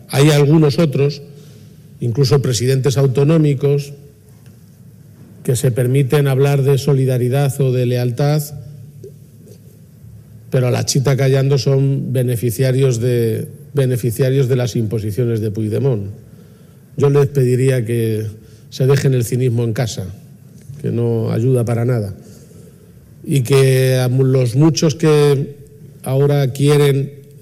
Yo les pediría que se dejen el cinismo en casa, que no ayuda para nada" ha dicho hoy el presidente de Castilla-La Mancha tras la reunión del Consejo de Gobierno extraordinario en el que se ha tratado la presentación del recurso de inconstitucionalidad ante el reparto del impuesto a la banca por parte del Gobierno central. garcia_page_210325_cinismo_pdmt.mp3 Descargar: Descargar